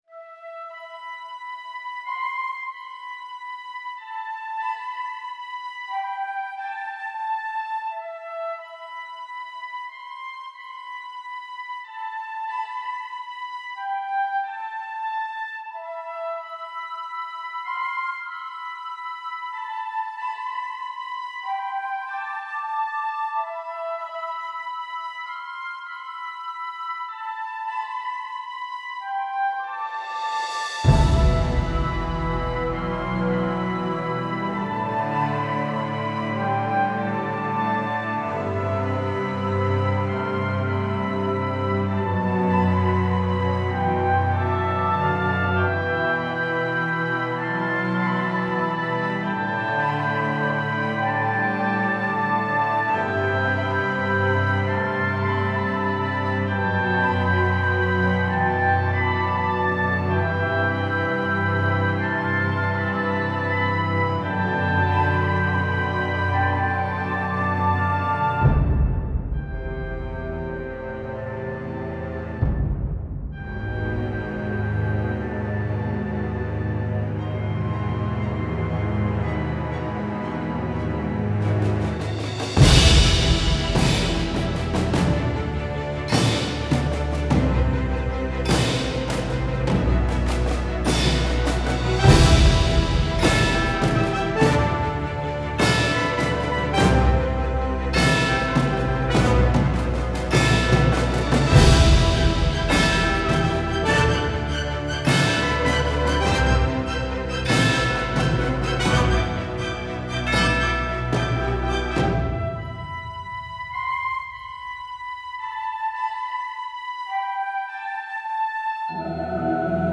Style Style Folk, Orchestral, Soundtrack
Mood Mood Epic, Intense, Mysterious
Featured Featured Bass, Bells, Brass +5 more